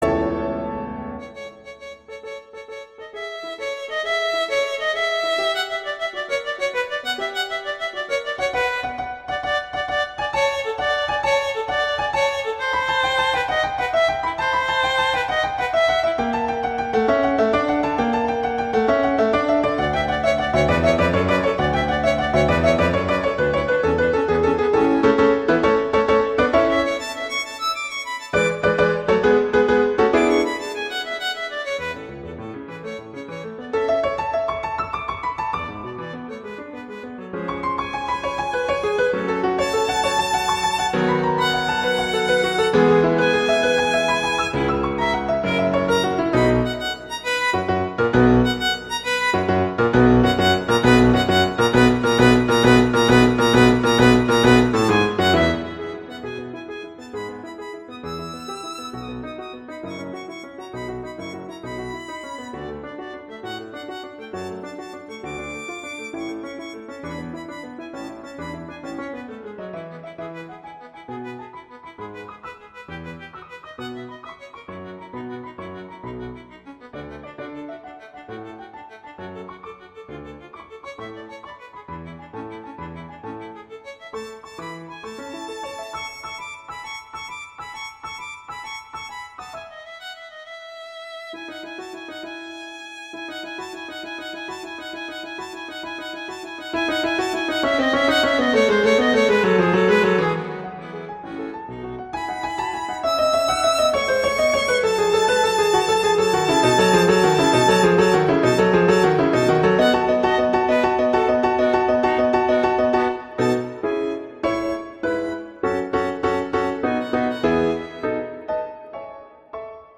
for violin and piano